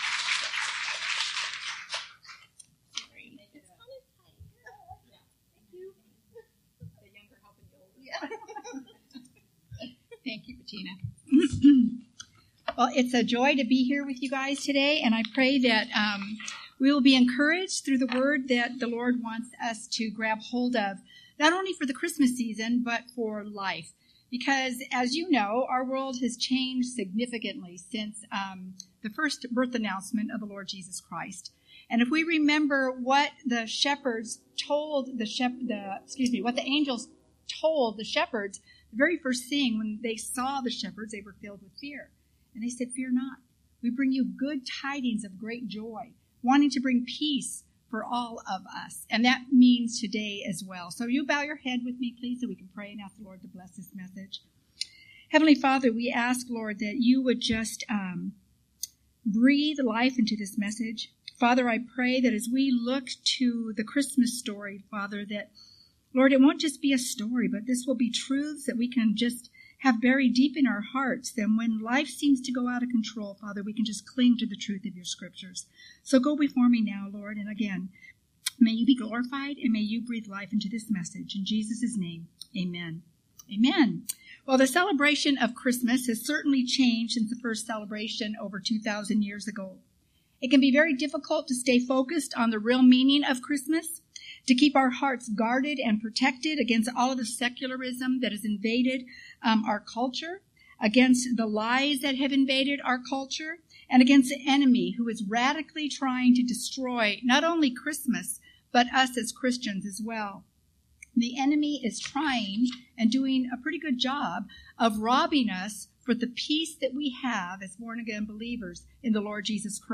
This is a test sermon